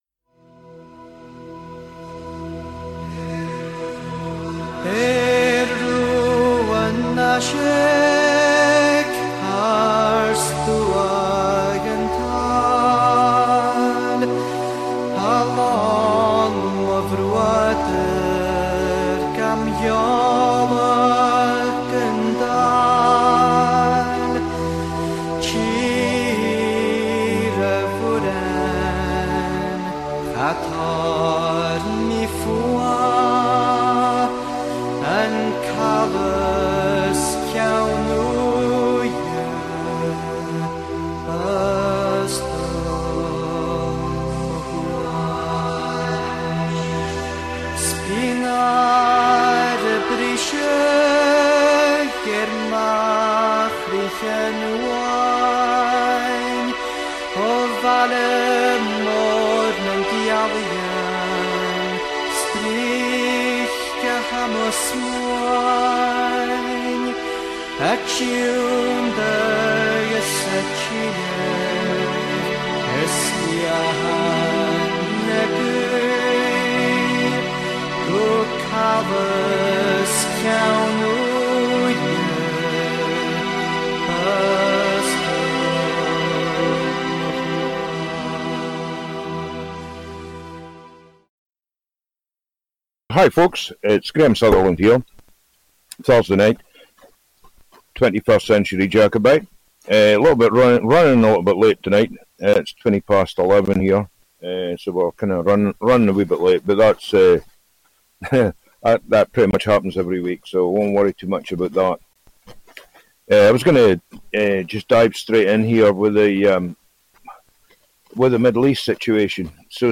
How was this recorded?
Callers are welcome to contribute. This weekly radio show broadcasts live every Thursday from Inverness, Scotland, transmitting real, uncensored and unsanitized philosophy, news and perspectives.